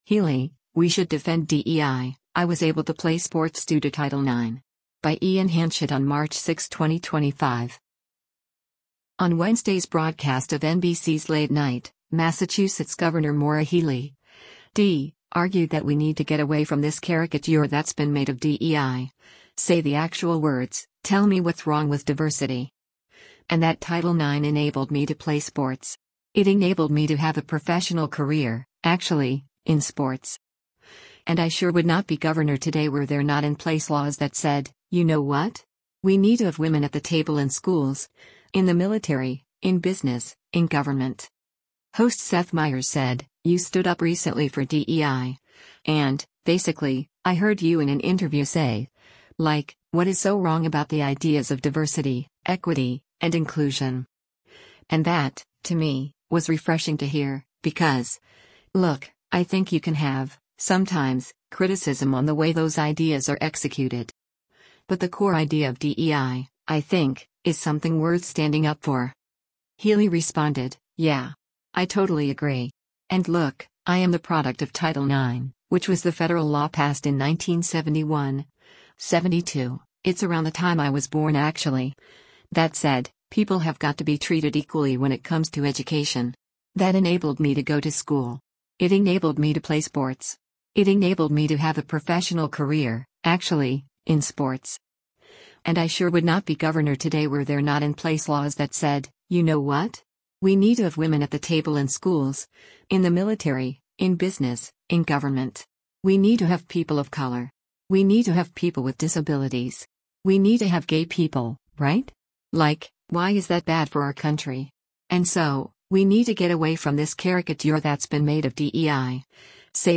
On Wednesday’s broadcast of NBC’s “Late Night,” Massachusetts Gov. Maura Healey (D) argued that “we need to get away from this caricature that’s been made of DEI, say the actual words, tell me what’s wrong with diversity.” And that Title IX “enabled me to play sports. It enabled me to have a professional career, actually, in sports. And I sure would not be Governor today were there not in place laws that said, you know what? We need to have women at the table in schools, in the military, in business, in government.”